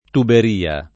tuberia
tuberia [ tuber & a ] s. f.